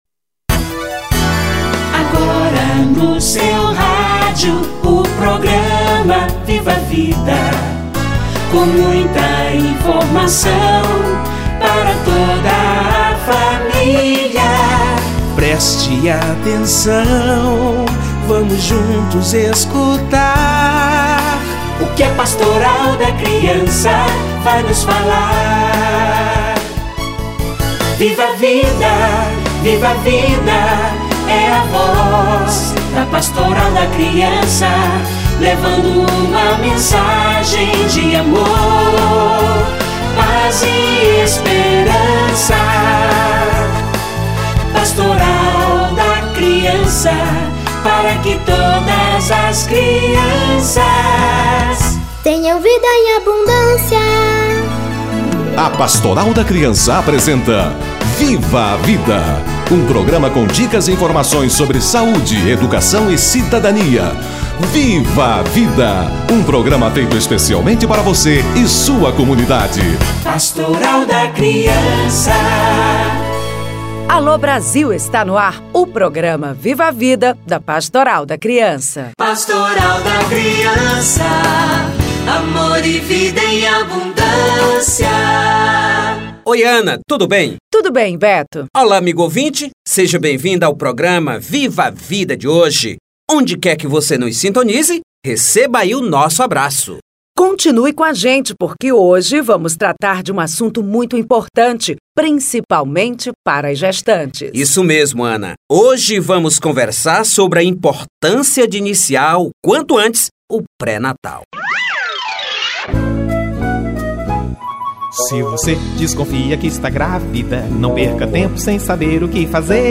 Mutirão em busca das gestante - Entrevista